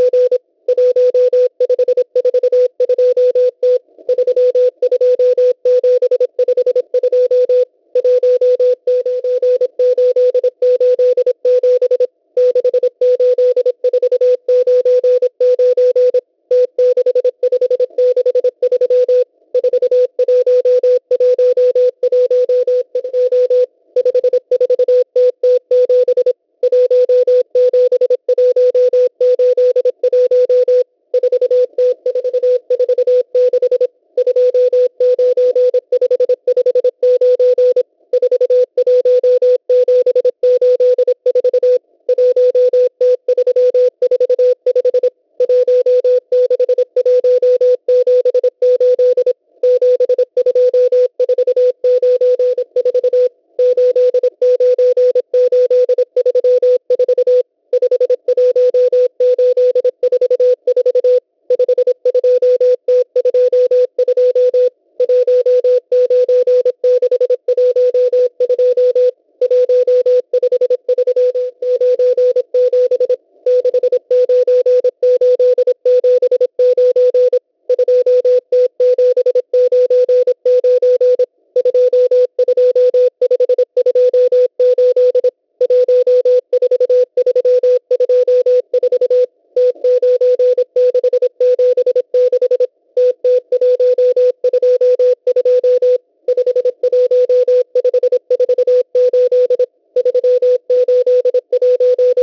CW
10544 khz